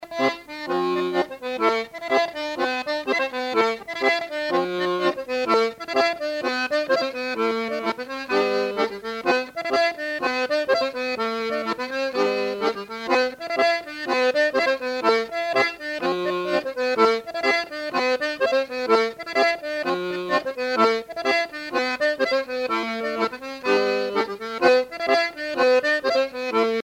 danse : bal (Bretagne)
Pièce musicale éditée